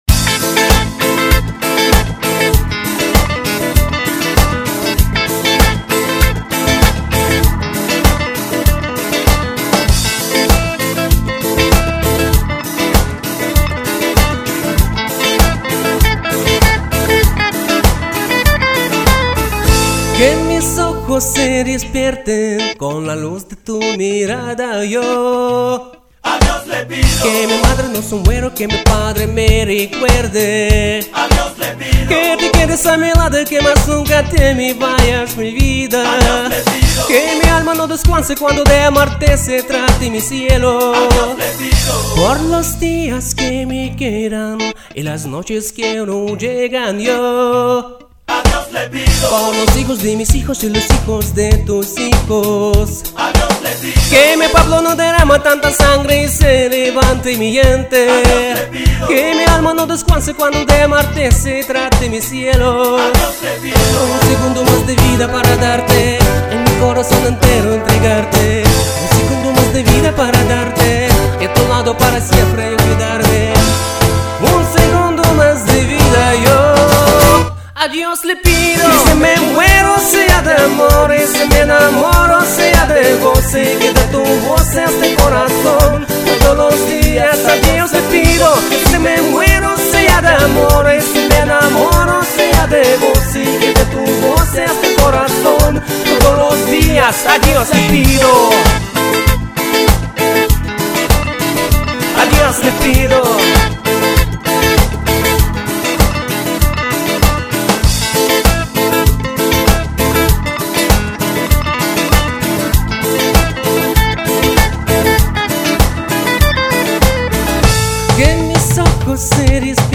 классный ринг! здоровские песни! отличные исполнения!)))))